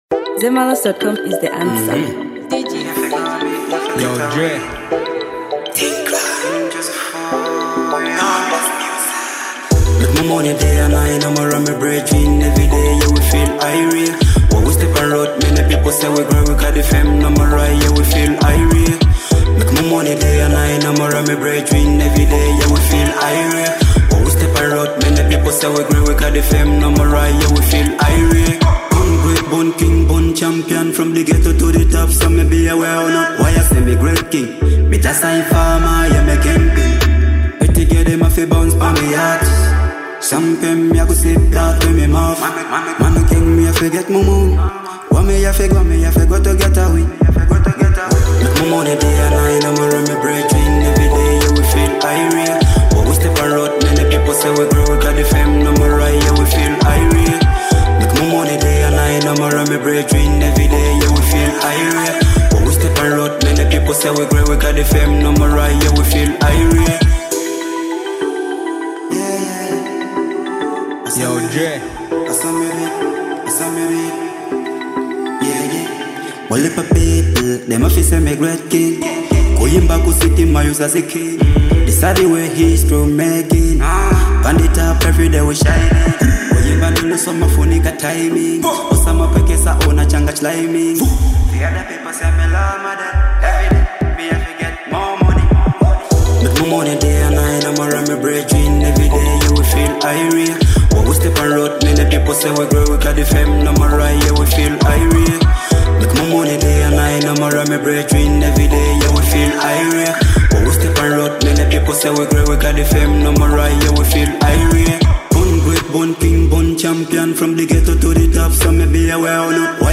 Genre: Dancehall.